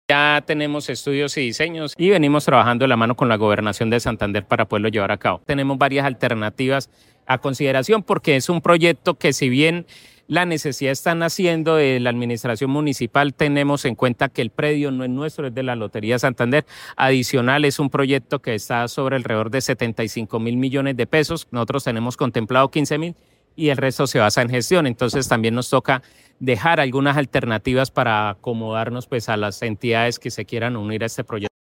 Secretario de Infraestructura, Gerson González